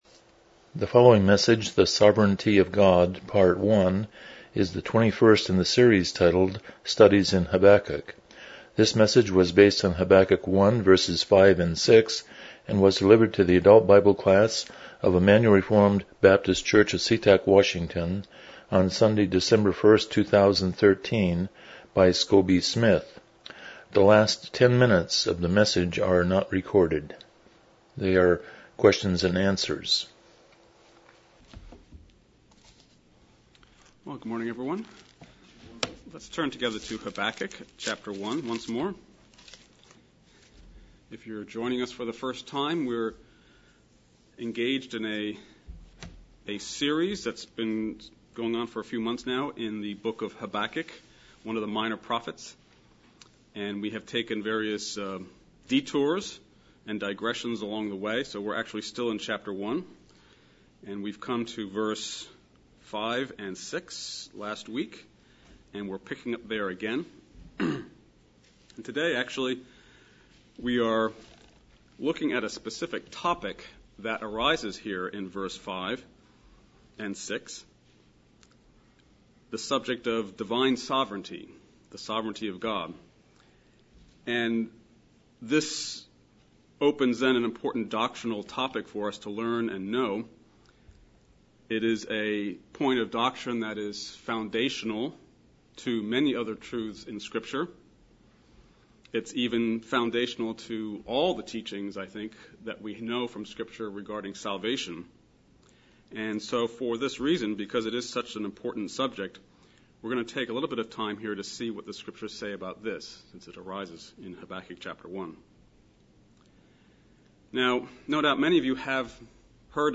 Studies in Habakkuk Passage: Habakkuk 1:5-6 Service Type: Sunday School « Thirst for God 22 The Sovereignty of God